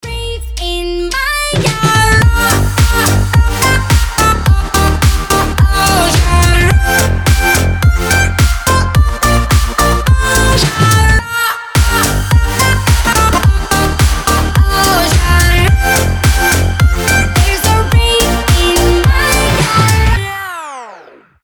громкие
веселые
энергичные
progressive house